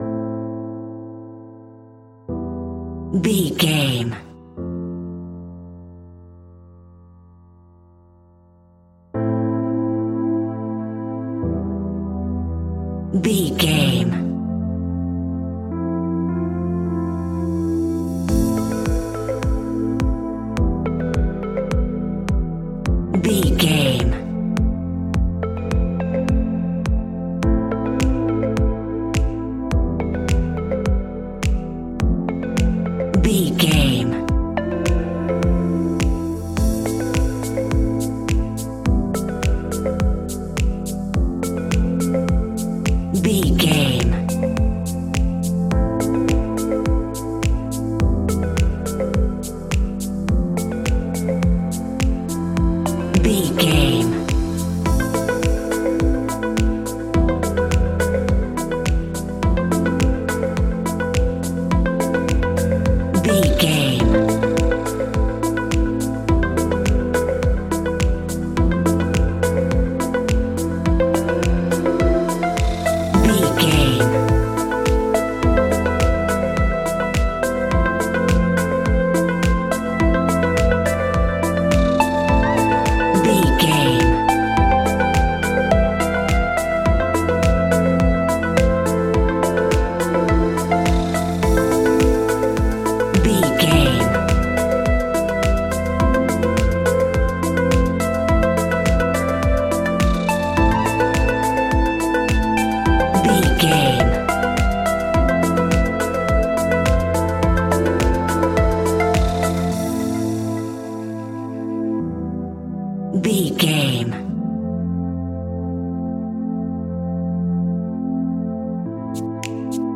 Evening Tropical House Full.
Aeolian/Minor
groovy
calm
smooth
dreamy
uplifting
piano
drum machine
synthesiser
house
instrumentals
synth bass